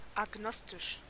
Ääntäminen
Synonyymit unsure godless Ääntäminen US : IPA : [æɡ.ˈnɑːs.tɪk] RP : IPA : /æɡ.ˈnɒs.tɪk/ Haettu sana löytyi näillä lähdekielillä: englanti Käännös Ääninäyte Substantiivit 1.